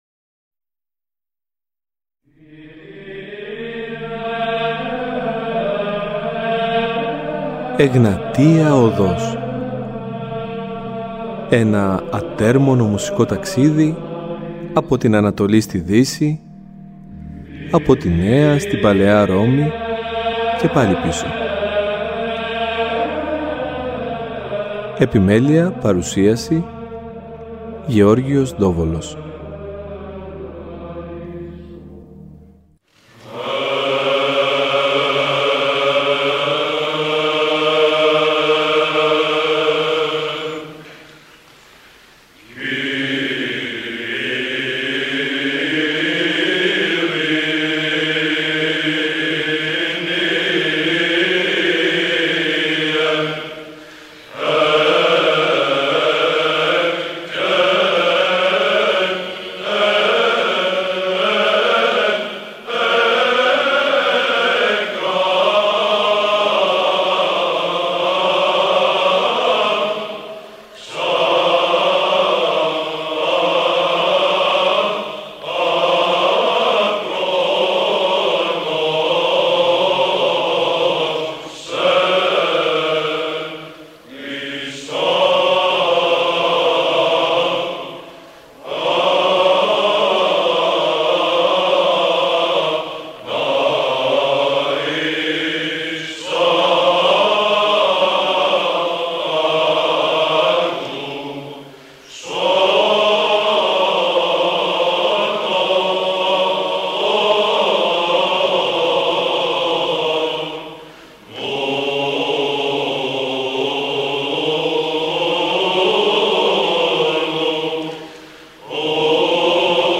Στα πλαίσια του πολύπτυχου αυτού αφιερώματος, στην εκκλησιαστική μουσική της Θεσσαλονίκης, είχαμε την χαρά να ακούσουμε σπάνια ηχογραφήματα από εμφανίσεις της χορωδίας, αλλά και να μάθουμε ακόμα περισσότερα για το ύφος και την ψαλτική τεχνοτροπία όπως αυτή ενδημεί στην συμπρωτεύουσα.